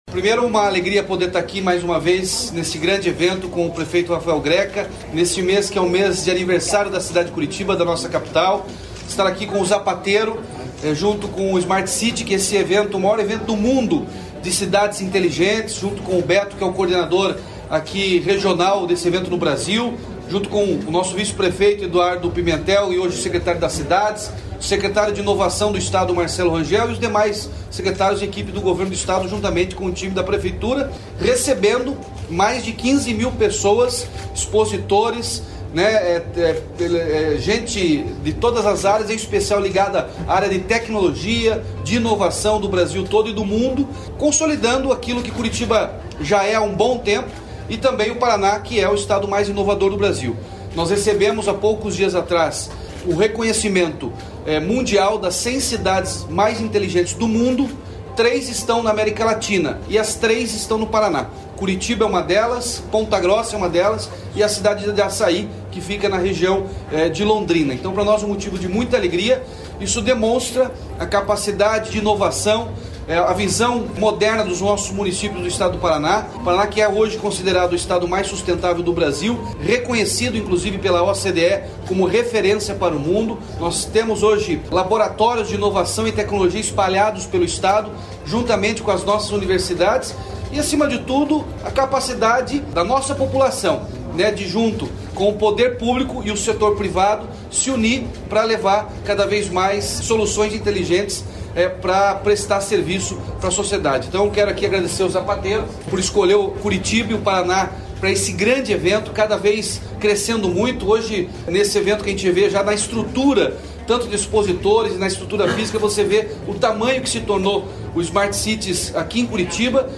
Sonora do governador Ratinho Junior sobre a participação do Estado na Smart City Expo Curitiba 2023